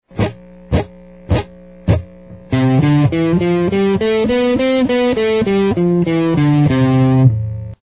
Scales and Modes on the Guitar
C-Major.mp3